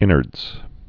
(ĭnərdz)